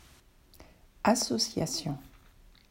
1. Association: Verein (assossjassjõ)